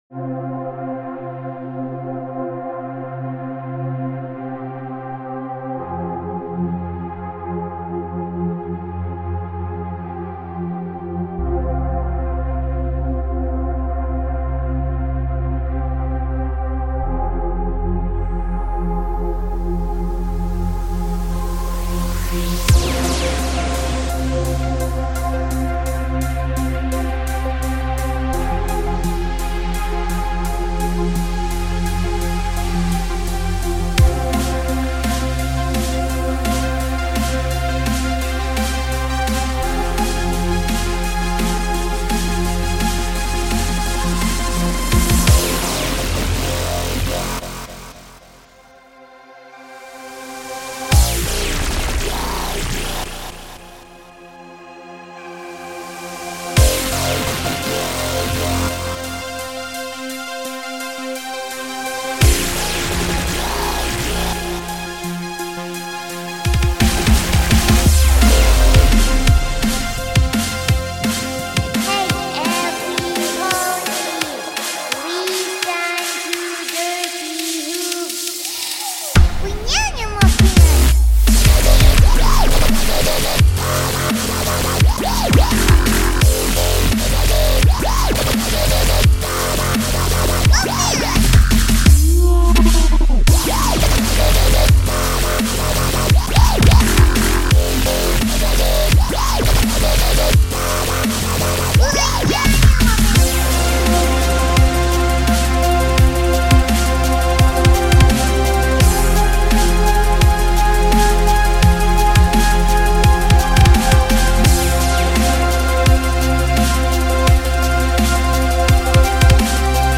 It's not dubstep... it's drumstep!